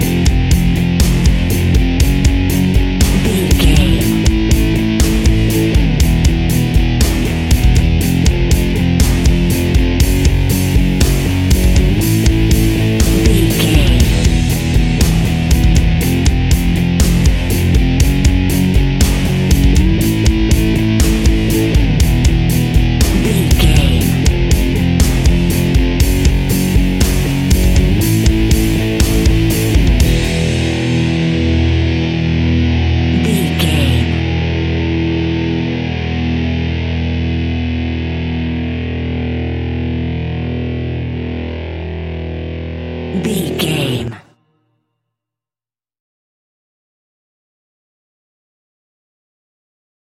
Aggressive Rock Metal Music 30 Sec Mix.
Epic / Action
Ionian/Major
hard rock
heavy metal
Rock Bass
heavy drums
distorted guitars
hammond organ